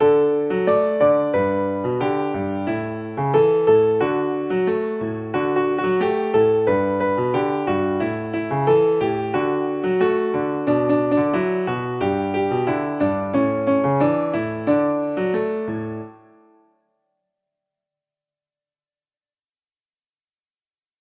Tune Key: C Major